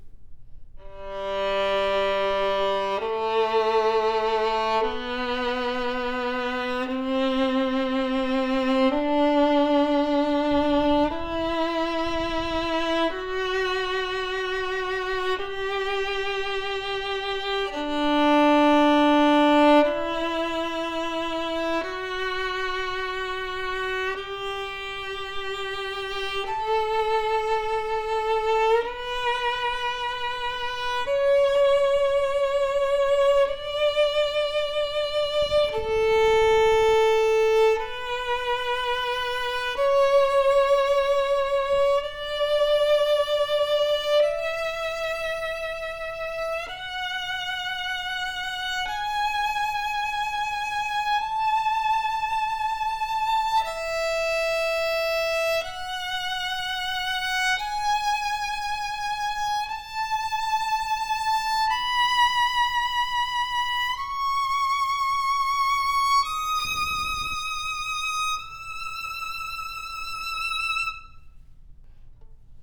Audio file ( Scale ):
A POWERFUL and OPEN sounding violin with deep texture, that’s on par with high dollars instruments.
Open, sonorous, booming tone that sings with great depth. Sweet and complex in mid range, strong projection that speaks with clear definition. Focused brilliant  E string with great sustain, sonorous and projective tone as the audio clip shows, highly projective sounding violin that can easily fill the hall.